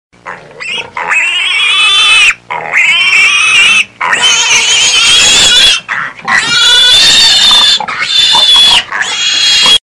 Kategorien: Tierstimmen